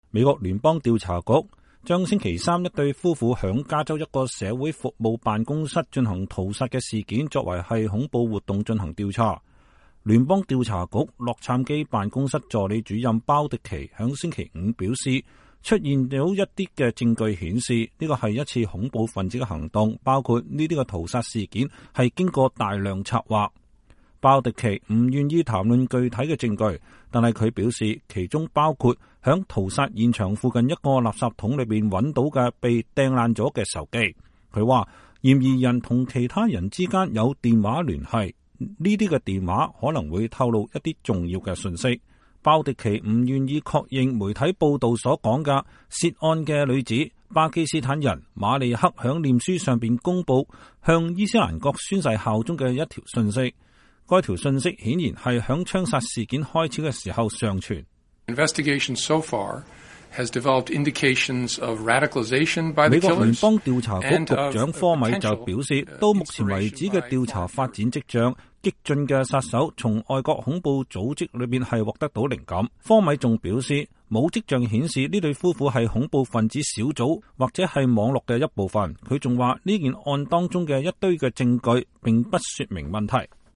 2015年12月4日,聯邦調查局洛杉磯辦公室的助理主任鮑迪奇在聖貝納迪諾舉行記者會。